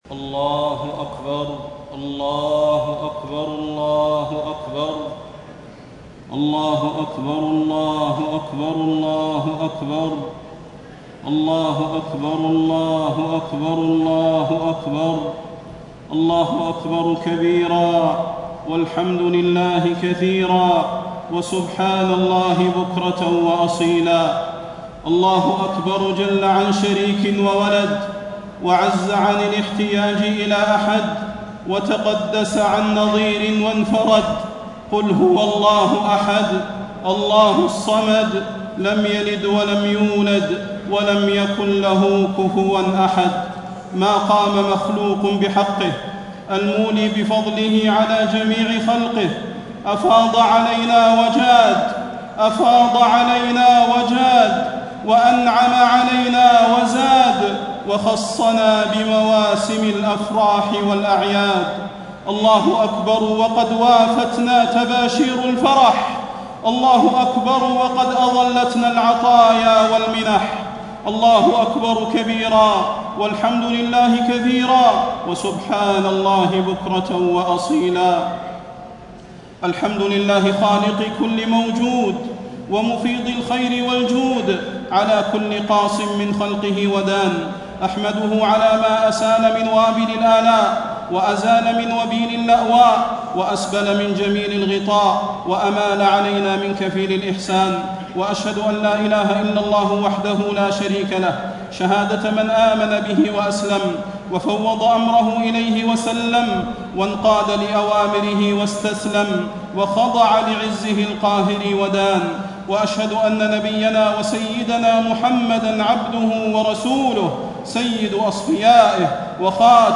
خطبة عيد الفطر - المدينة - الشيخ صلاح البدير
تاريخ النشر ١ شوال ١٤٣٥ هـ المكان: المسجد النبوي الشيخ: فضيلة الشيخ د. صلاح بن محمد البدير فضيلة الشيخ د. صلاح بن محمد البدير خطبة عيد الفطر - المدينة - الشيخ صلاح البدير